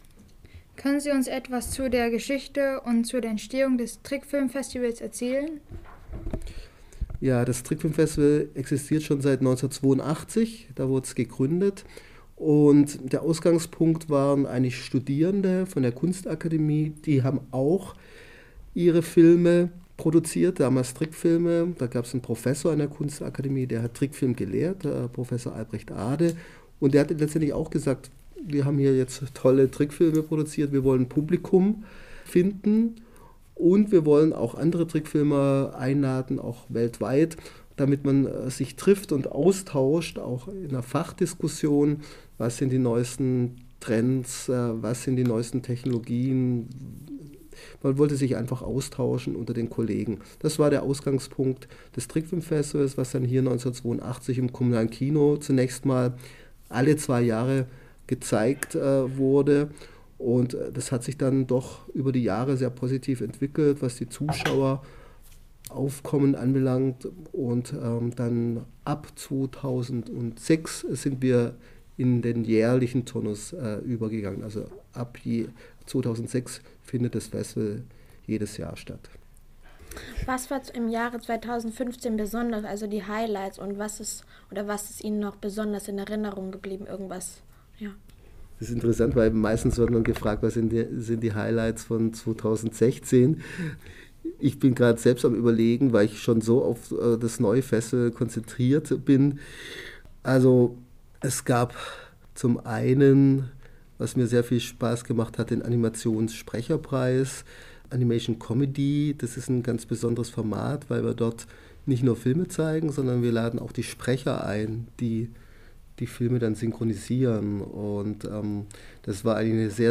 Gespräche mit Medienprofis
Die jungen Reporterinnen und Reporter haben an zwei Terminen unterschiedliche Fachleute in unterschiedlichen Berufen geschnappt und sie zu verschiedenen Themen im Fachbereich Medien befragt.